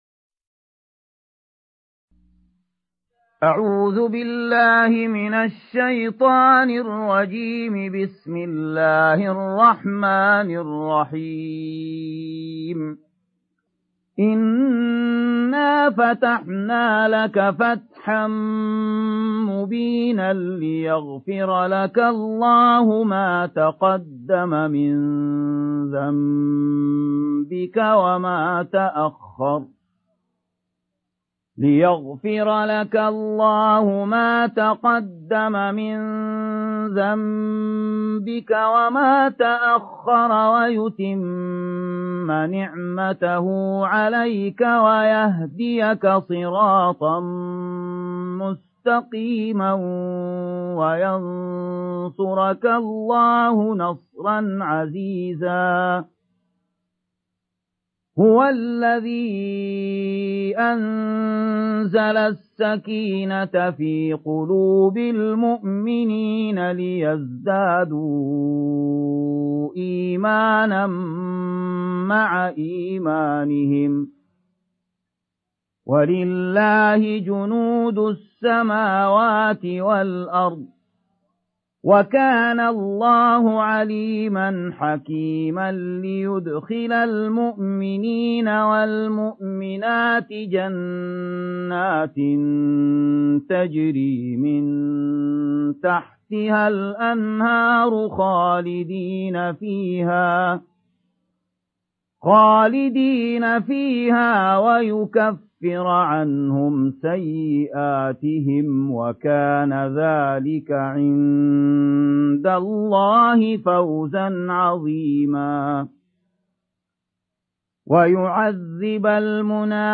سورة الفتح مدنية عدد الآيات:29 مكتوبة بخط عثماني كبير واضح من المصحف الشريف مع التفسير والتلاوة بصوت مشاهير القراء من موقع القرآن الكريم إسلام أون لاين